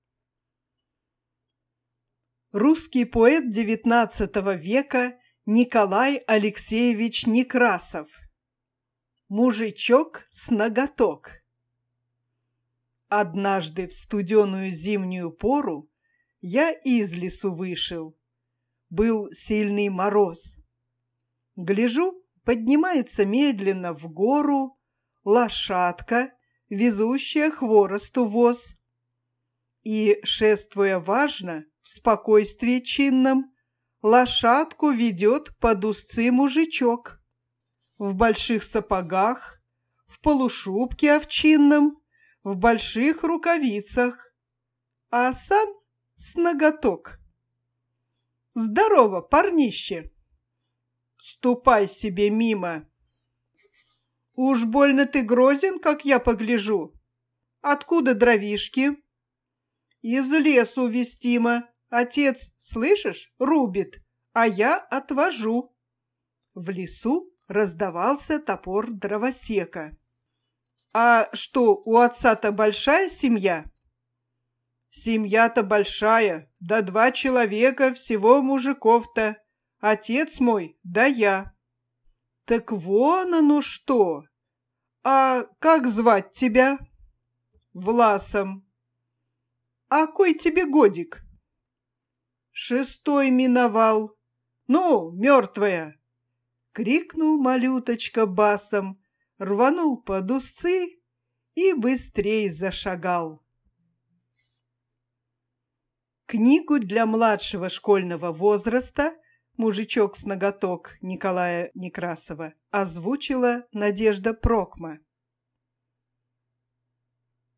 Аудио книга русского поэта XIX века Николая Алексеевича Некрасова "Мужичок с ноготок", для детей младшего школьного возраста, издательство "Малыш", 1977 года. Отрывок из поэмы "Крестьянские дети", 1861 год написания.